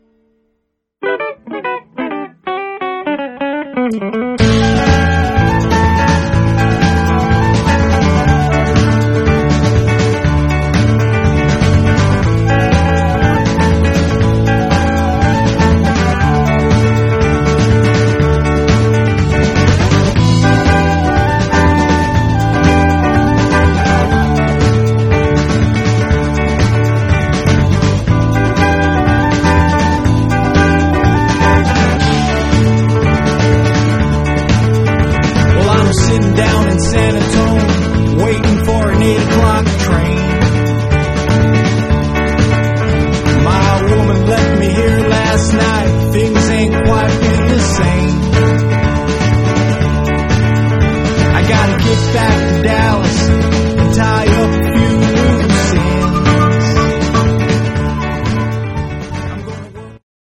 features smoking horns and a rock-solid rythm section
incredible vocal harmonies and ferocious live energy.
Jamband
Rock
World Rhythms